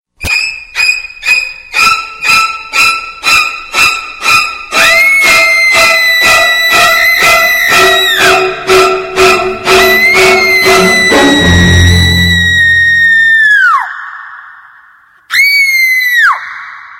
Halloween Horror - Botão de Efeito Sonoro